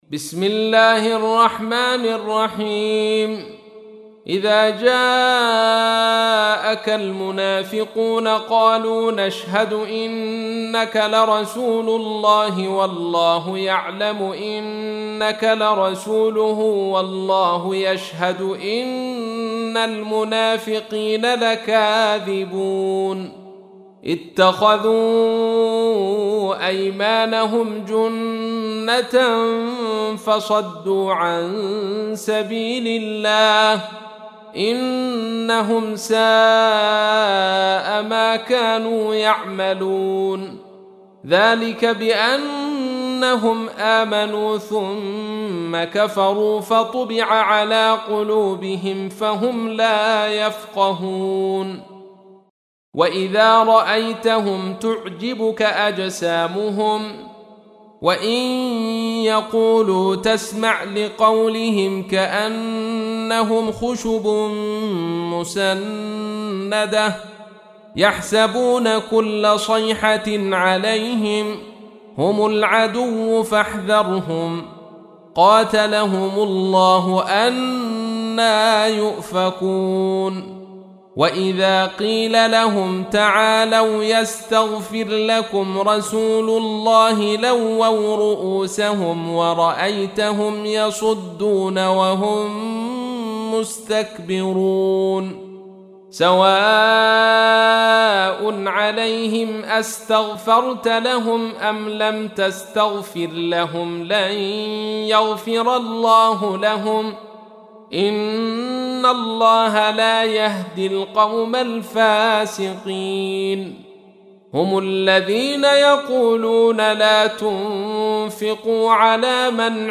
تحميل : 63. سورة المنافقون / القارئ عبد الرشيد صوفي / القرآن الكريم / موقع يا حسين